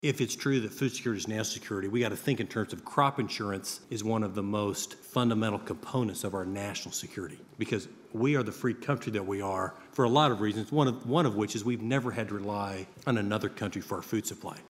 A panel discussion, hosted by Farm Journal Foundation and Kansas State University Tuesday at the Stanley Stout Center, focused on how agricultural innovations can mitigate global hunger and malnutrition.